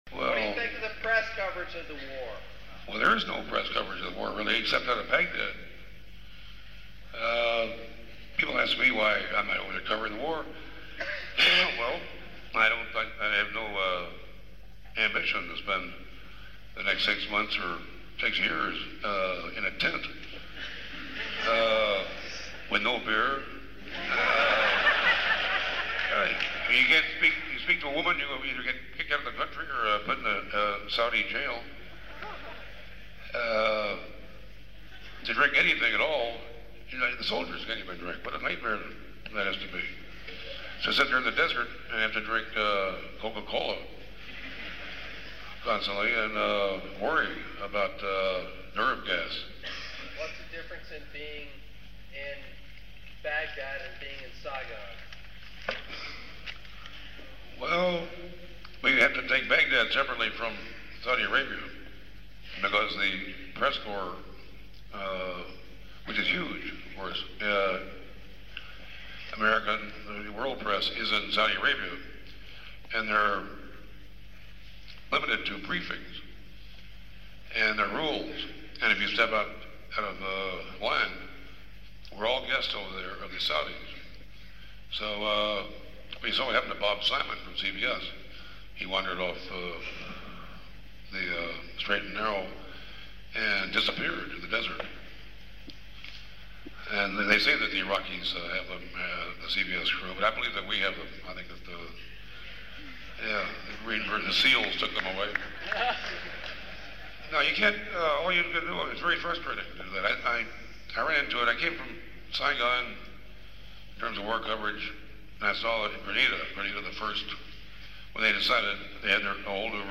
Live audio of Hunter S. Thompson at Washington & Lee University on April 4th 1991.